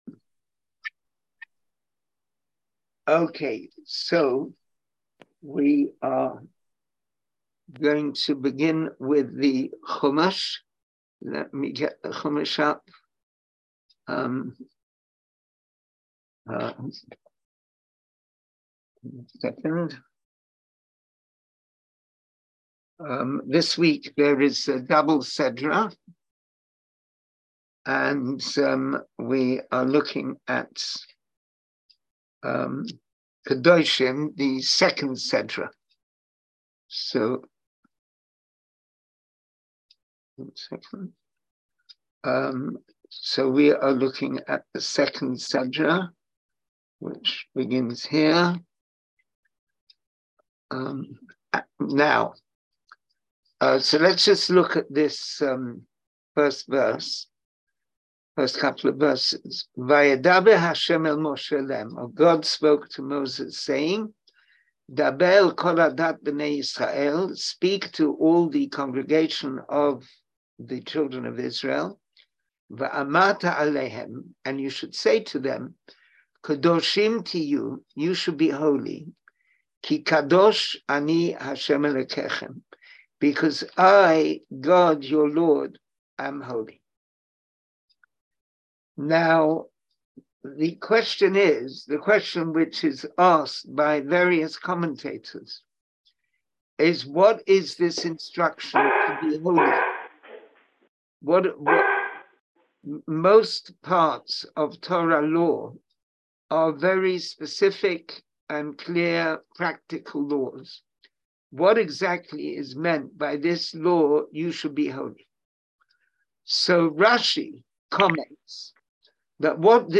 Class audio Listen to the class Watch the Video Class material Hebrew Summary of Discourse English Summary of Discourse Join the class?